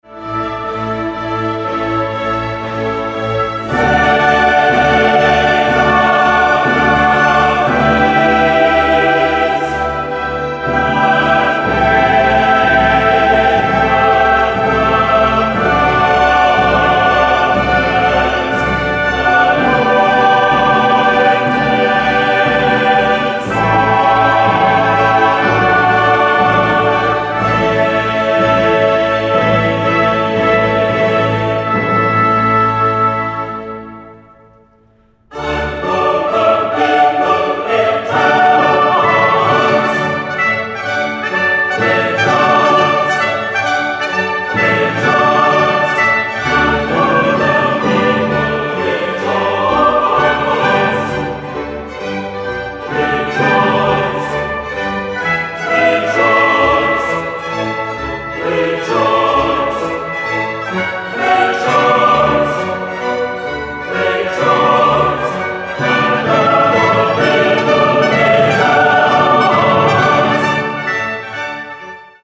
Ключевые слова спрятаны под звуком "Пи-и-и-и".